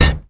FlareHitB.wav